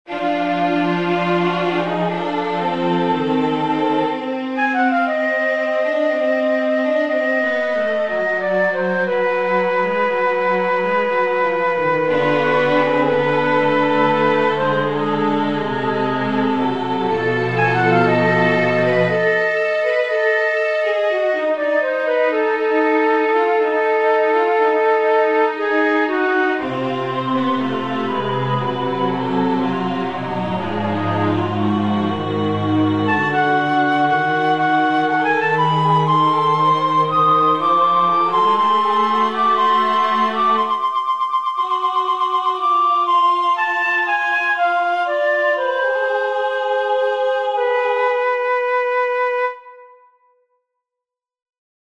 I once set The Song Of The Third Thames Maiden to music—it's a study in octatonic harmony and the mp3 file is